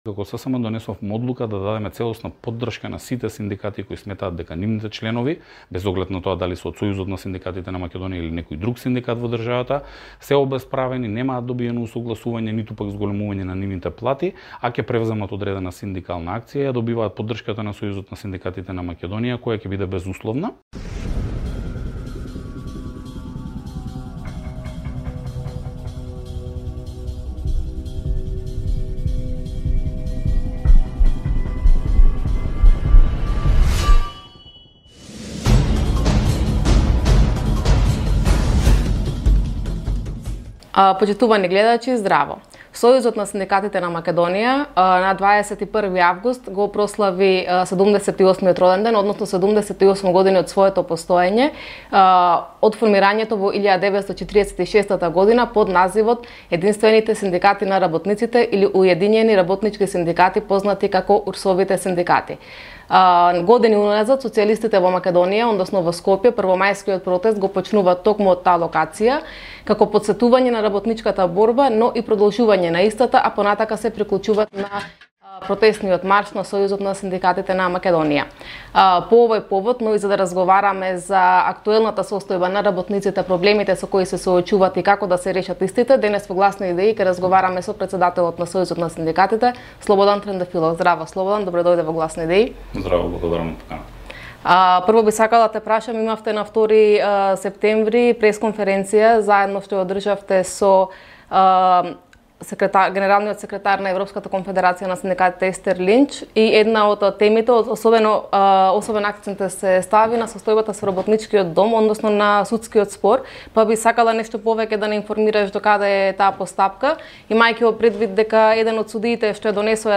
ИНТЕРВЈУ: Кој и зошто го напаѓа ССМ?